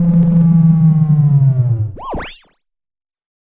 描述：与"Attack Zound03"相似，但有一个长的衰减，在衰减结束时有一个奇怪的声音效果。这个声音是用Cubase SX中的Waldorf Attack VSTi制作的。
Tag: 电子 SoundEffect中